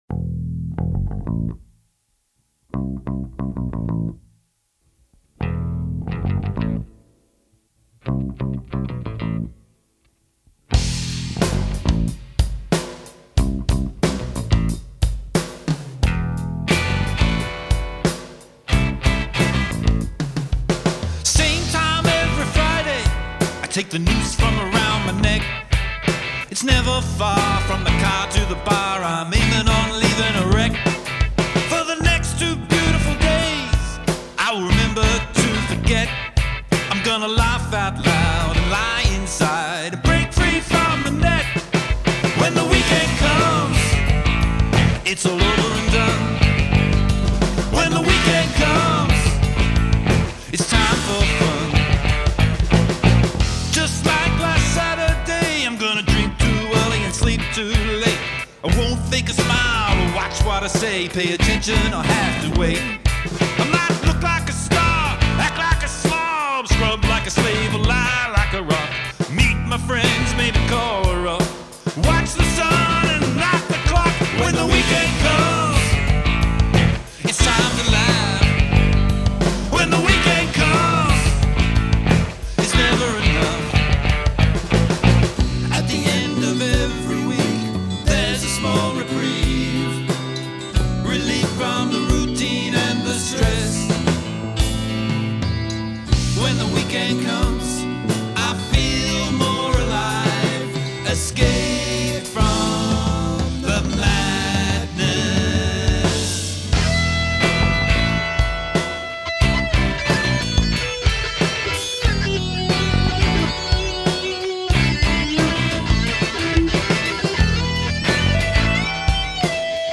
Drums & Acoustic Guitar
Guitar & Vocals
Bass & Vocals
Lead Guitar